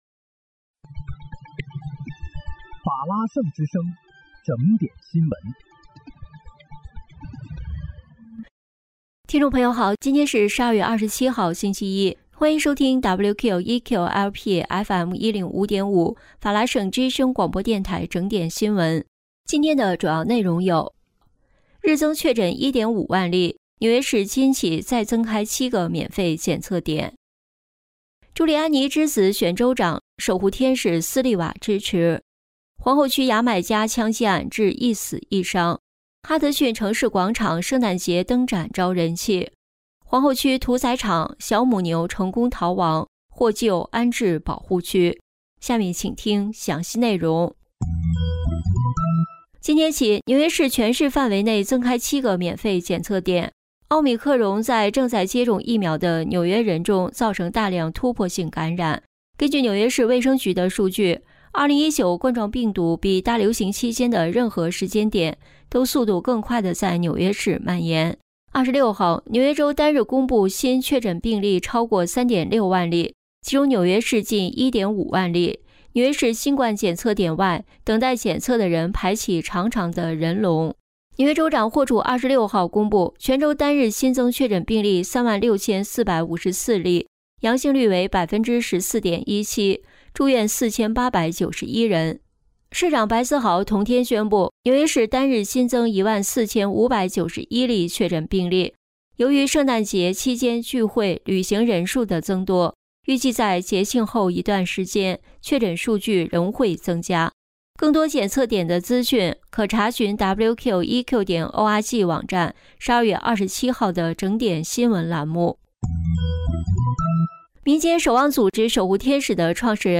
12月27日（星期一）纽约整点新闻